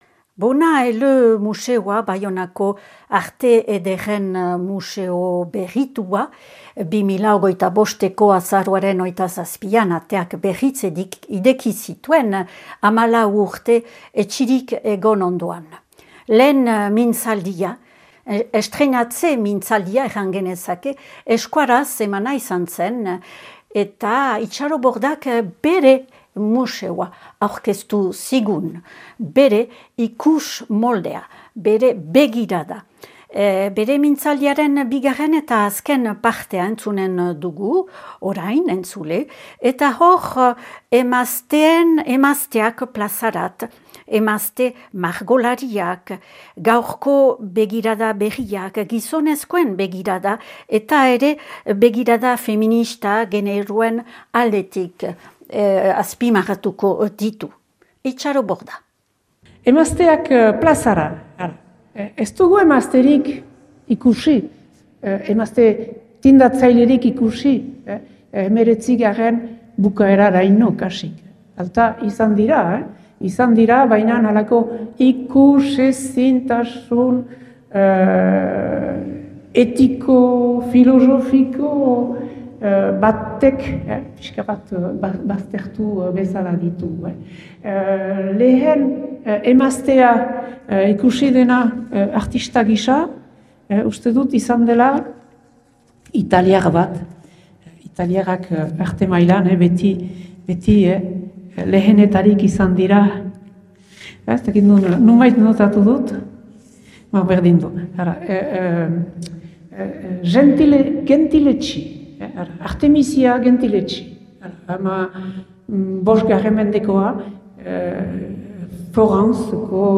Baionako Arte Ederren museo berrituak antolatu lehen mintzaldia, Itxaro Borda idazle eta poetak artearekin eta museoarekin duen begirada partekatzen zigula.